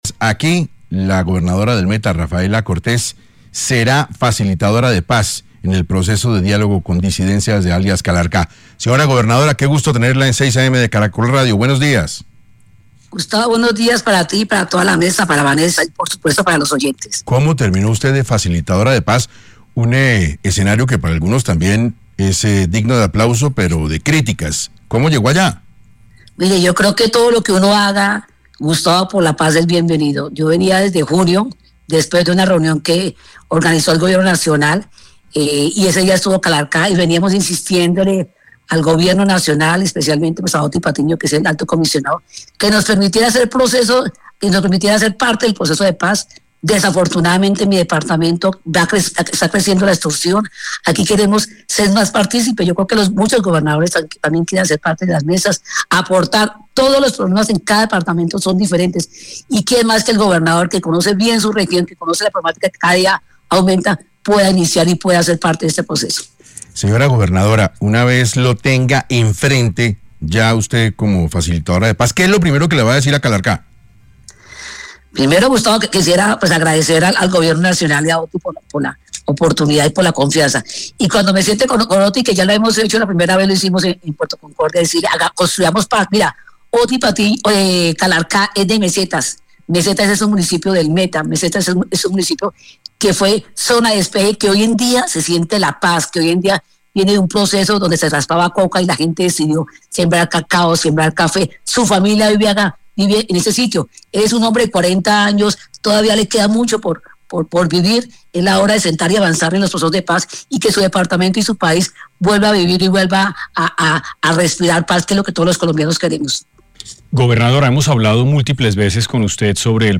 La gobernadora de este departamento, Rafaela Cortés, estuvo en los micrófonos de 6AM Hoy por Hoy dialogando sobre su nuevo cargo de Facilitadora de Paz en las negociaciones con alias “Calarcá”.
Rafaela Cortés estuvo en entrevista en 6AM Hoy por Hoy, tras el nombramiento que le hizo el Comisionado de Paz como Facilitadora de Paz.